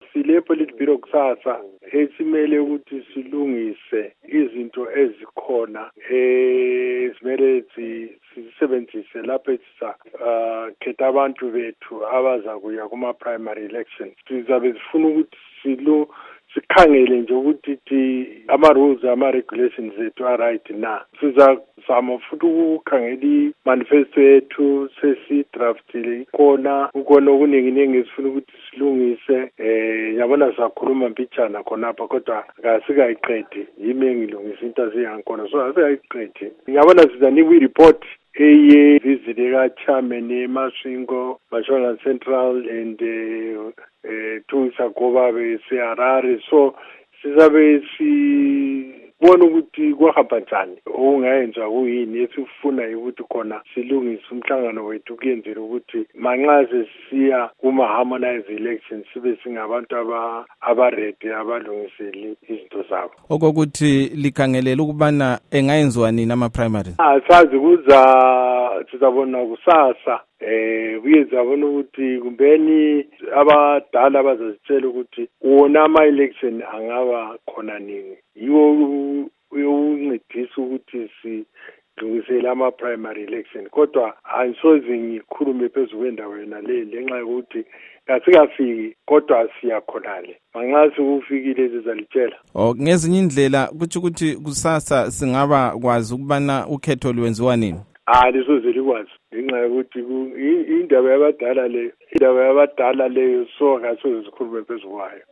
ingxoxo lomnu. rugare gumbo